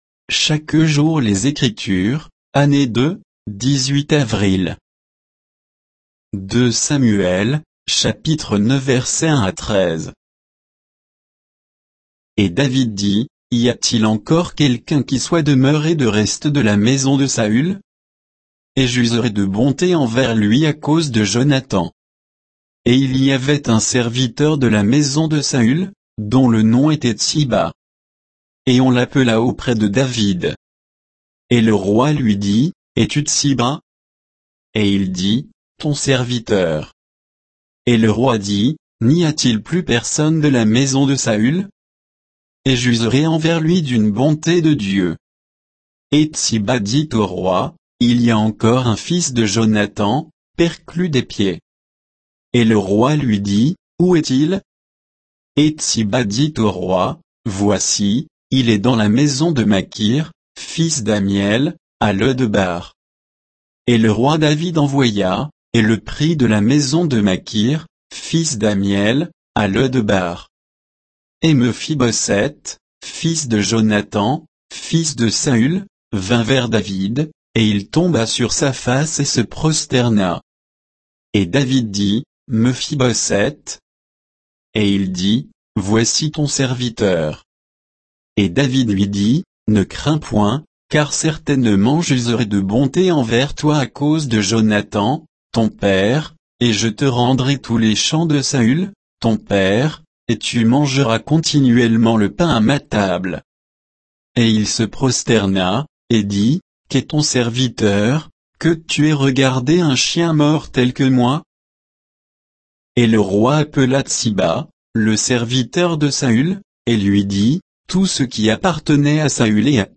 Méditation quoditienne de Chaque jour les Écritures sur 2 Samuel 9